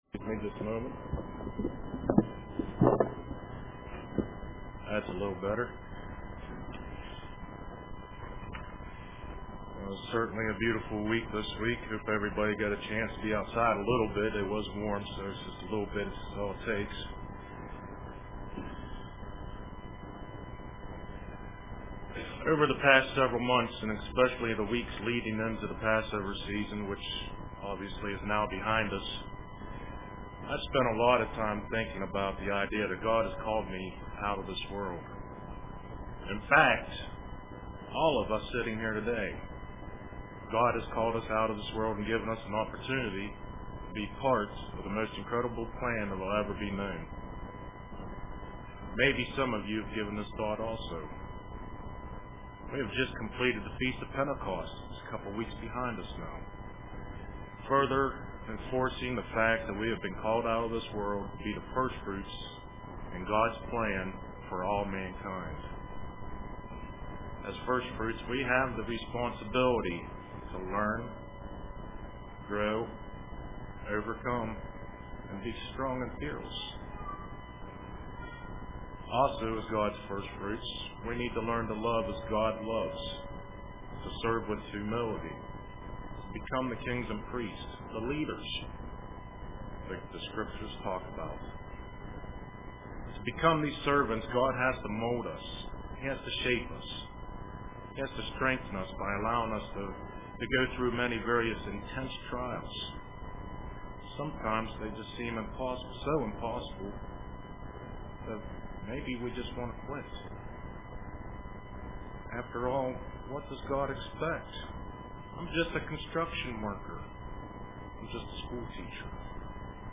Print Press Toward the Mark UCG Sermon Studying the bible?